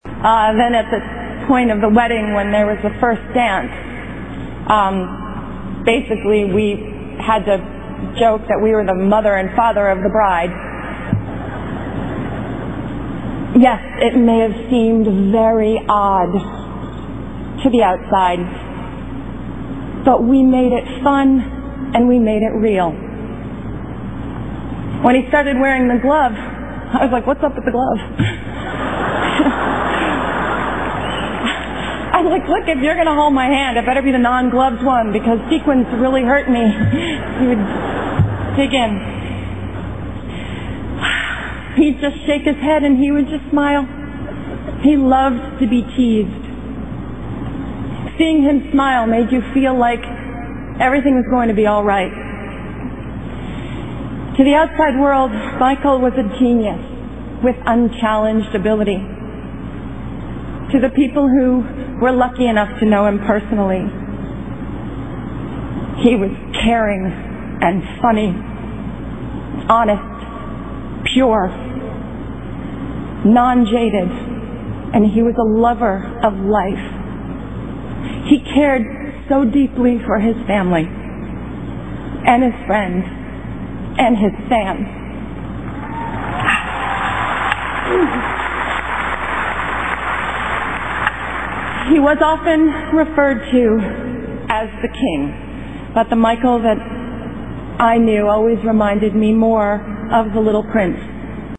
偶像励志英语演讲10:波姬·小丝在迈克尔·杰克逊追悼会上发言(3) 听力文件下载—在线英语听力室